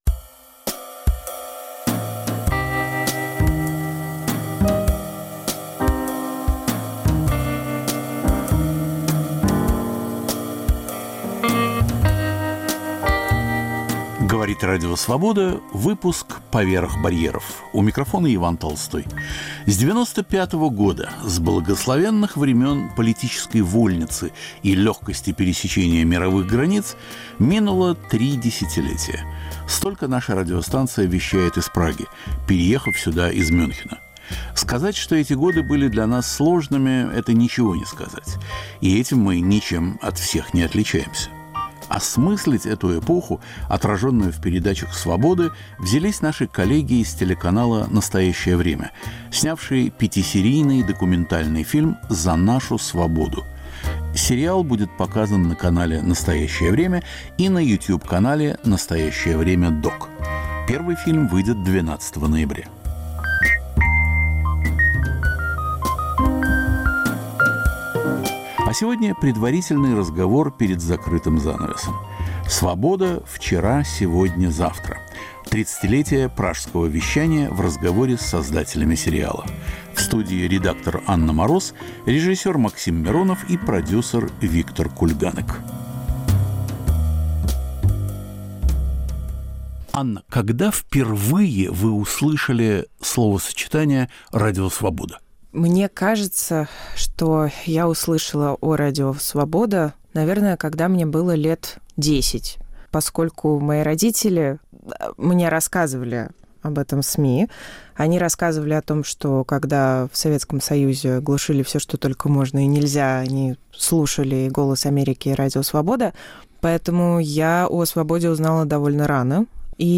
Круглый стол, посвященный этому сериалу, рассказывает об этапах работы, о задачах и взглядах создателей на радио, которое сегодня снова "в изгнании".